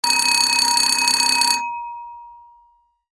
požární zvon
Hlasitost v 1m95 dB
FIREBELL.mp3